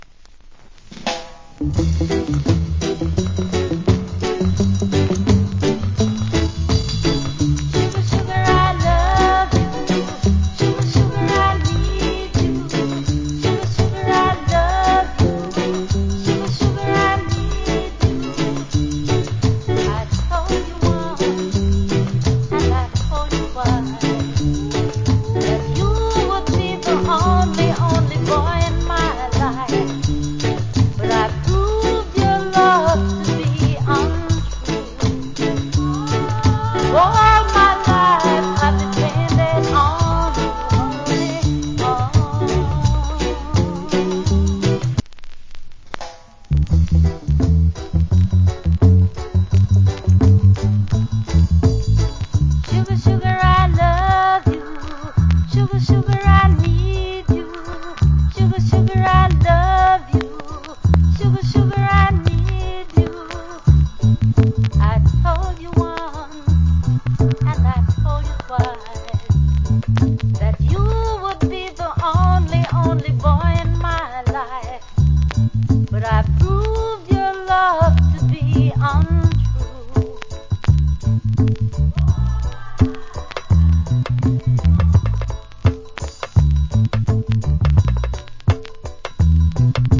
Female Vocal.